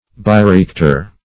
bioreactor \bi`o*re*ac"tor\, n.